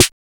ANA-MIX SN2.wav